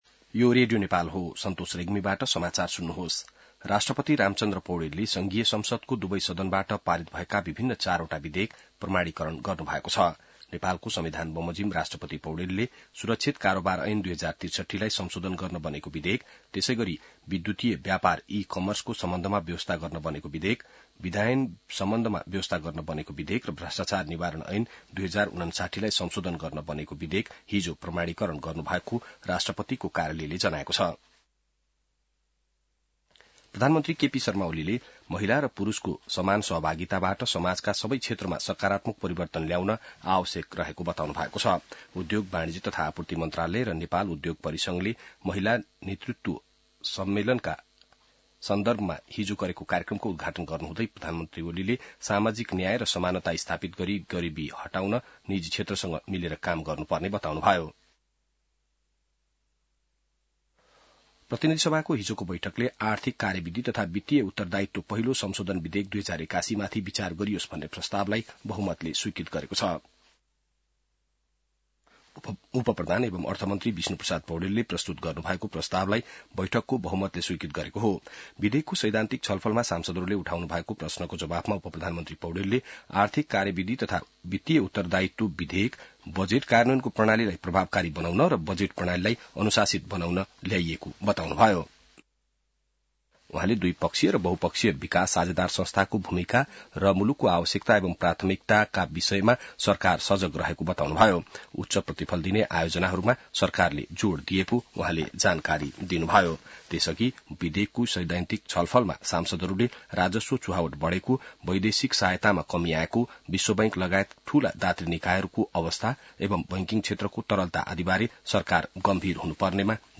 बिहान ६ बजेको नेपाली समाचार : ४ चैत , २०८१